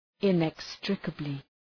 inextricably.mp3